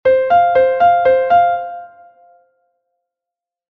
3/4: 6 corcheas Do Fa alternadas